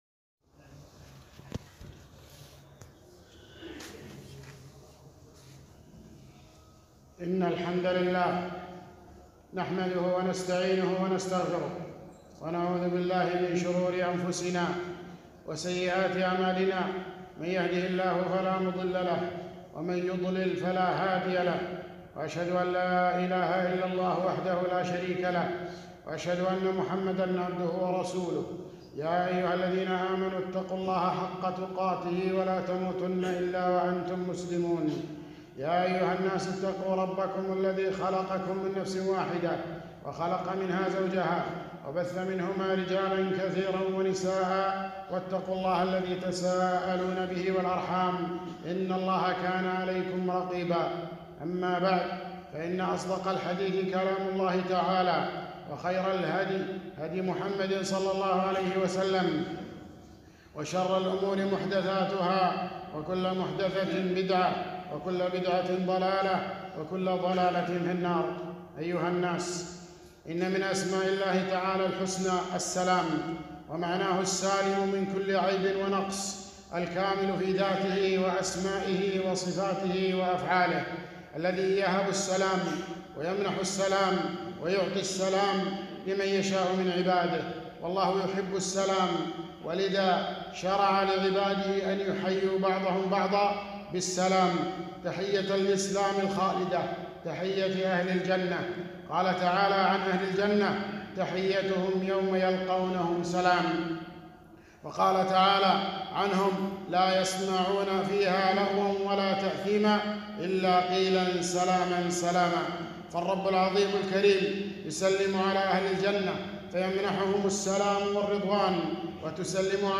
خطبة - السلام تحية أهل الإسلام